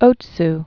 (ōts)